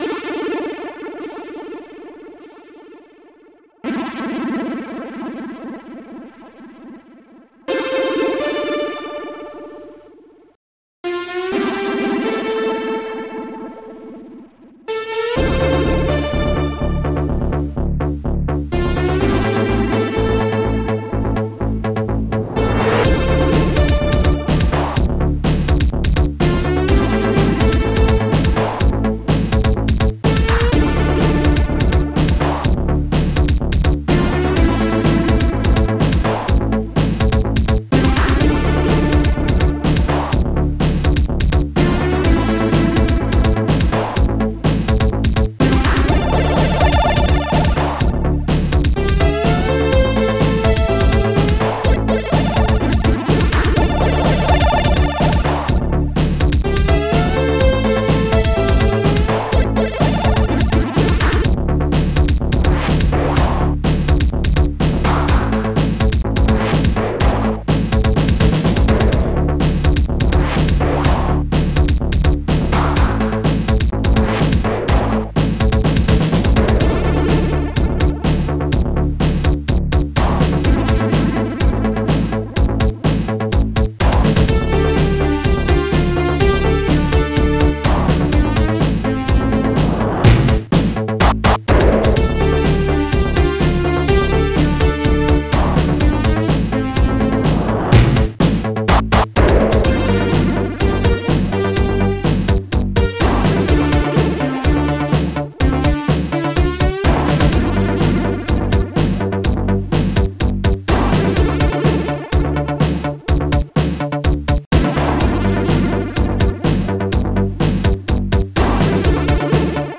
Protracker Module
st-12:bassdrum17 st-12:snare18 st-12:snare17 st-09:shortstring st-06:reverb2